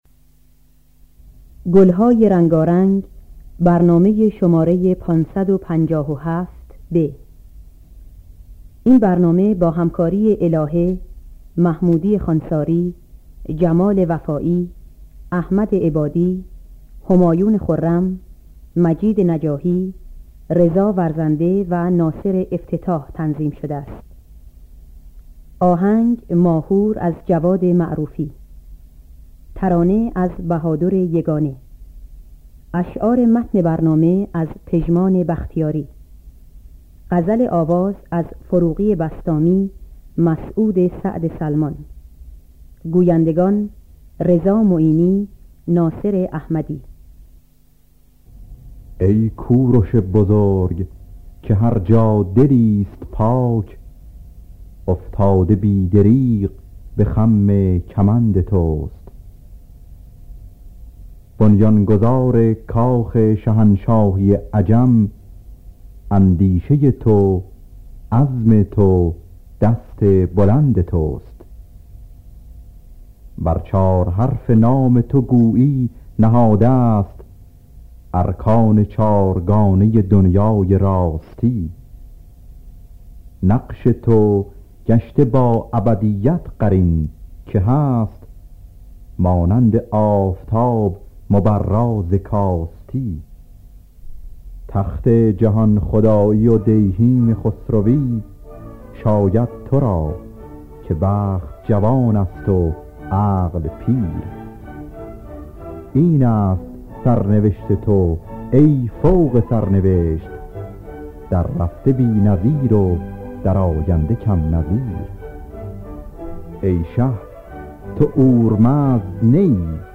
در دستگاه ماهور